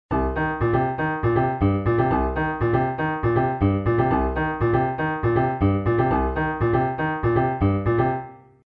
Funk Rhythm Exercise
Here is a little funk exercise that emulates this back and forth groove on the piano. Note that some of the attacks are played on off-beats, which creates a forward-moving groove.
It is derived from the C blues scale, but the notes are arranged in a very rhythmical manner to create a funky pattern. Note that both hands are played one octave lower than written (8vb).
funk-exercise.mp3